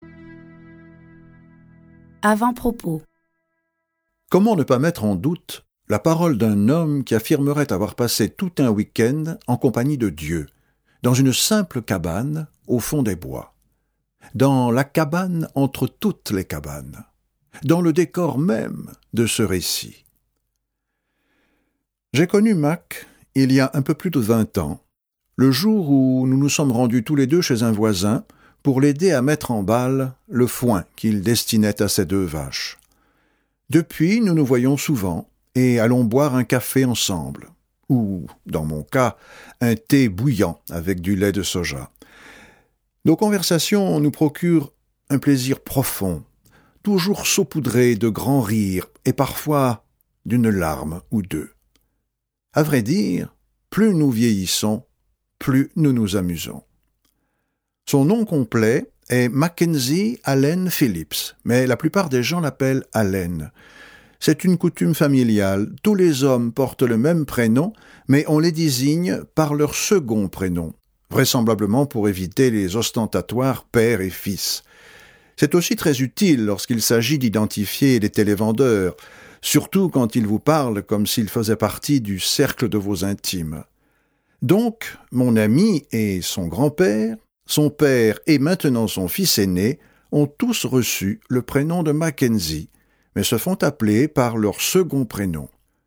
Click for an excerpt - La cabane de W. Paul Young